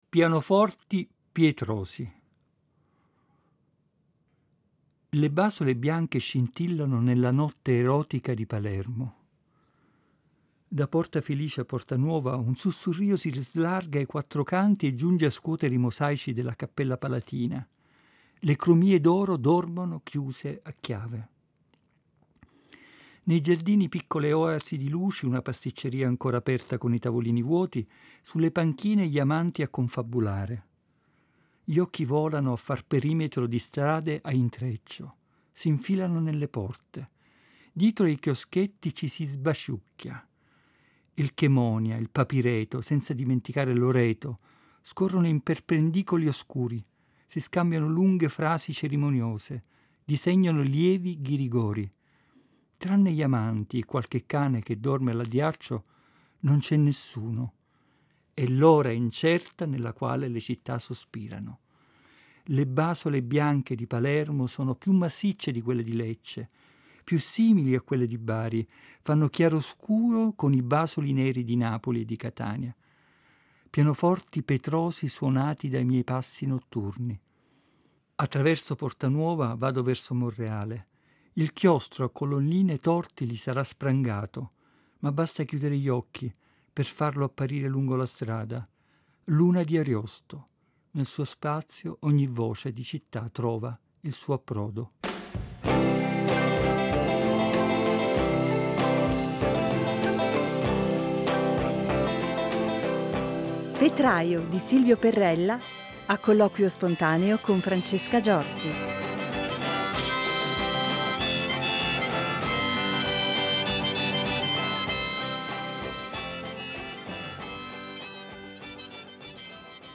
a colloquio spontaneo